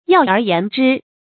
要而言之 yào ér yán zhī 成语解释 概括地说，简单地说。